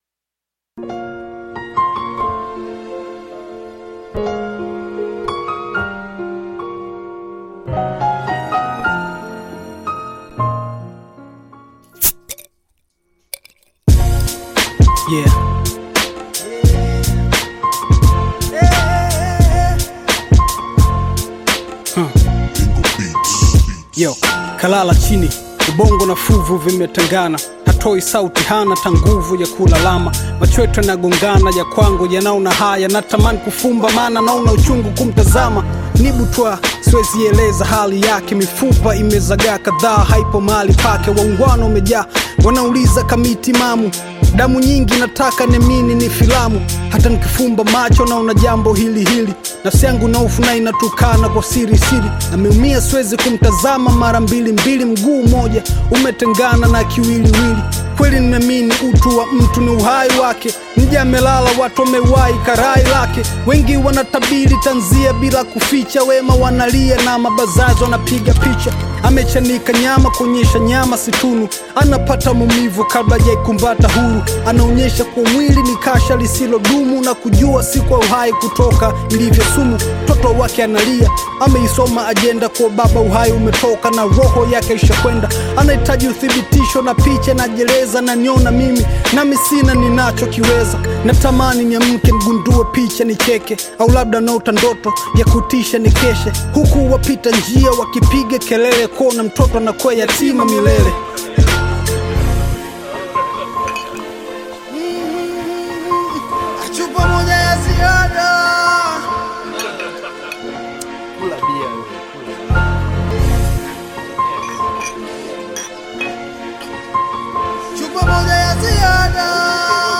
Tanzanian Bongo Flava artist and rapper
Bongo Flava song